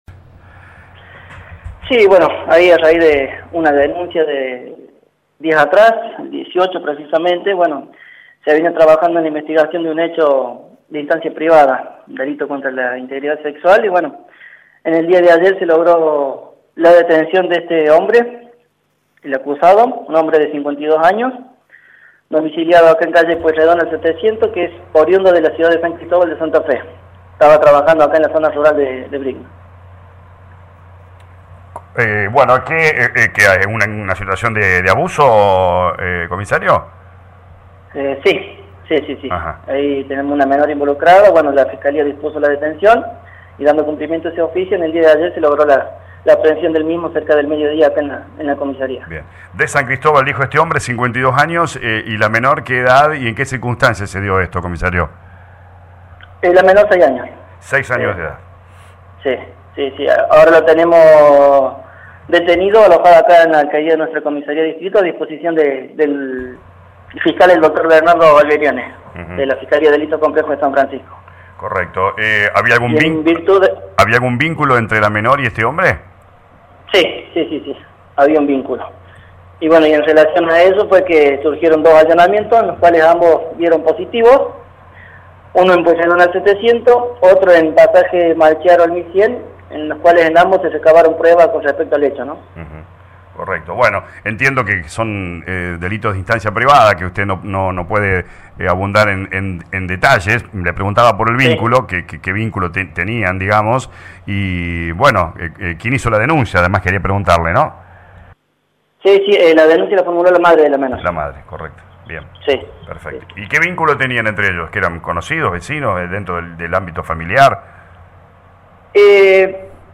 en diálogo con LA RADIO 102.9.